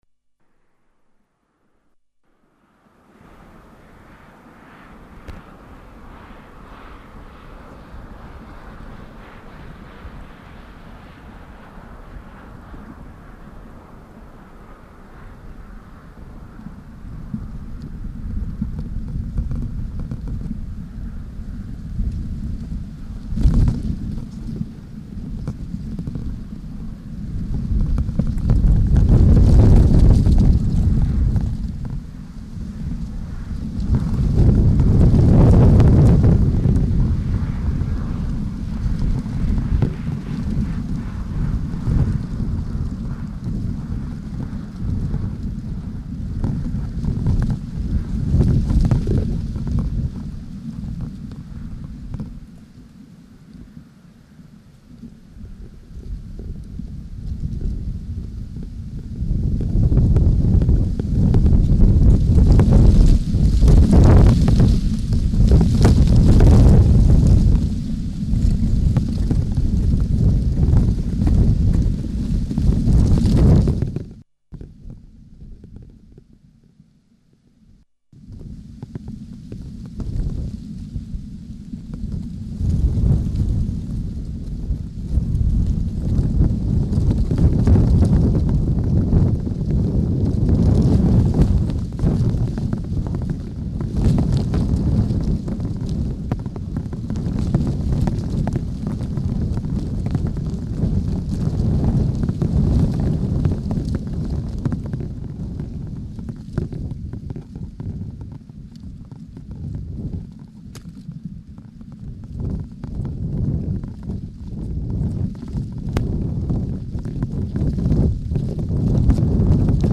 Field Recording Series by Gruenrekorder
Genre: Ambient Field Recordings / Phonography
The disc starts off with some gentle rumbling. The sounds of wind blowing, leaves and sticks crunching under foot, but mostly just the wind. There are long stretch’s of silence in between the more ear-catching sounds, with just the faintest hints of life during the pauses.